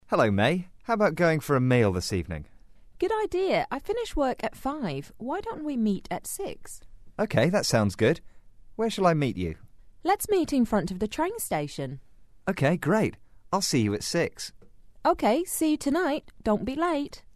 英语初学者口语对话第42集：今晚一起吃饭怎么样？